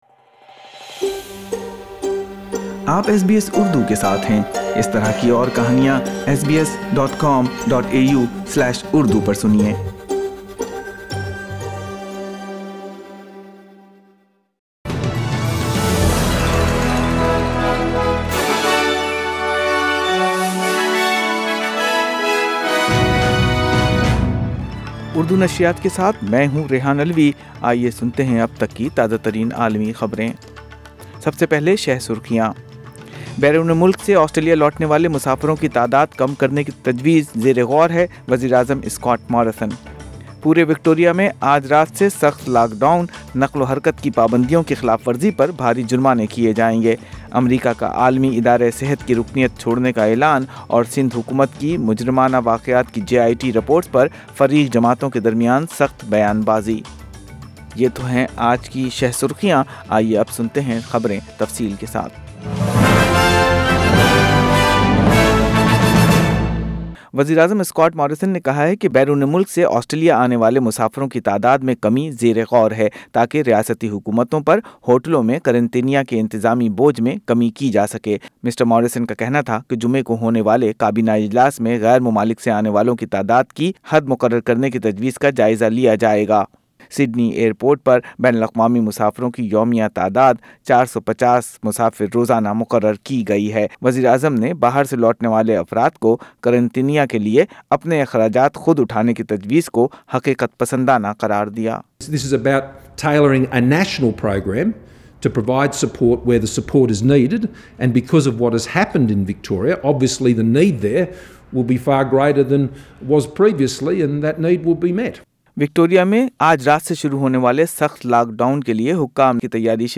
اردو خبریں 8 جولائی 2020